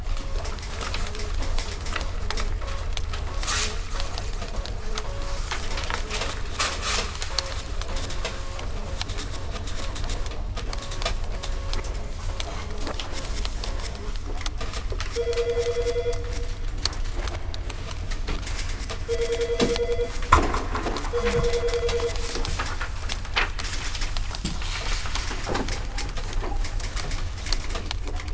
office.wav